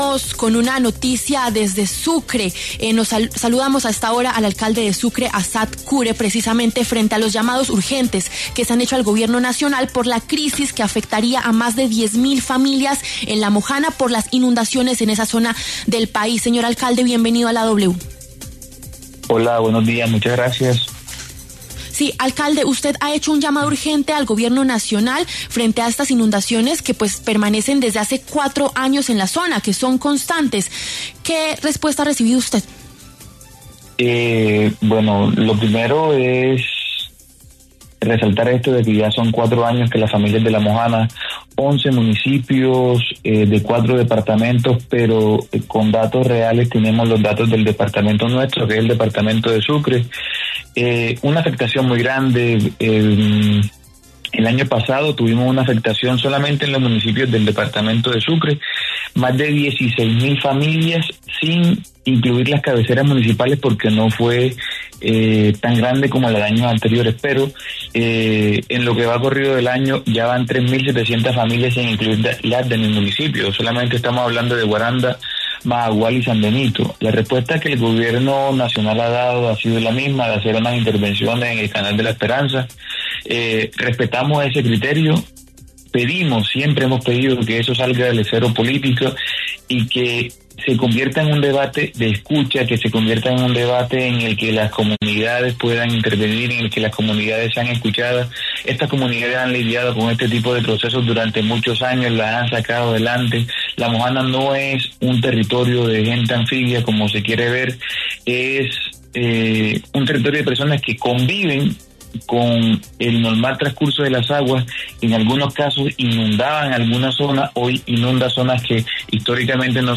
Assad Cure, alcalde de Sucre, habló este domingo, 11 de mayo, en los micrófonos de W Fin De Semana para referirse a la situación que viven más de 10.000 familias en La Mojana y el sur de San Jorge, en Sucre, debido al desbordamiento del río Cauca.